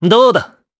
Esker-Vox_Attack2_jp.wav